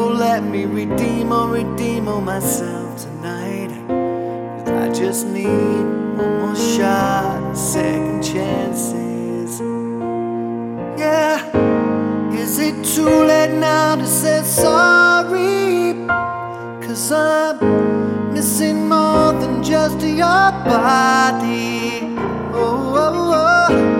With Lead Vocals